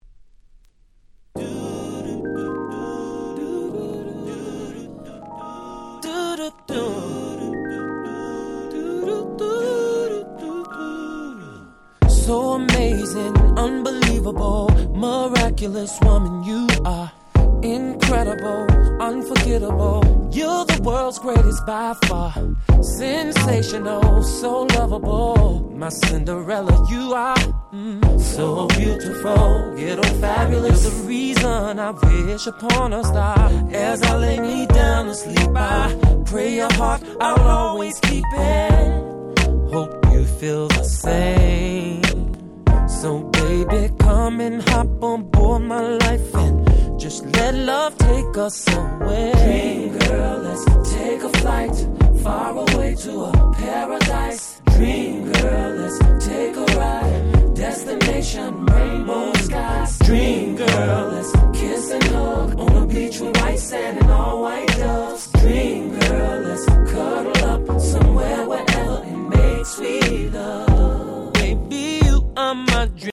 03' Super Hit R&B !!